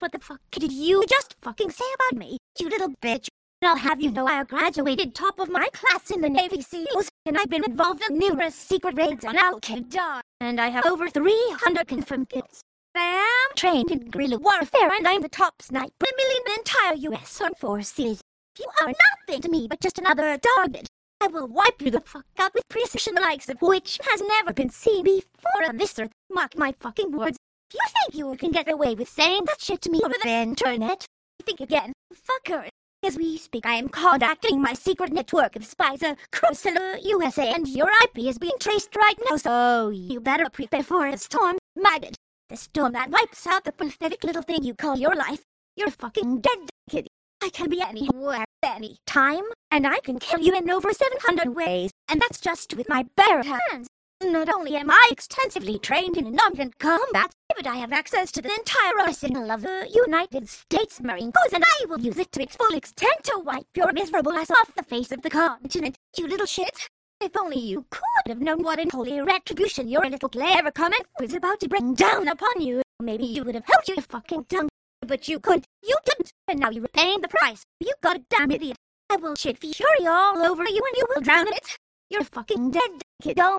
This exists! It's called "unit selection", and was an early method for generating high quality speech before everything became statistical.
But I still like this technique because it feels like a YTPMV shitpost (same technique, after all), but also has moments where it sounds more natural than any TTS because it uses the real samples.